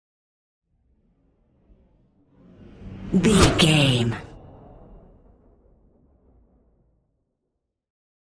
Whoosh super fast
Sound Effects
Fast
futuristic
intense
whoosh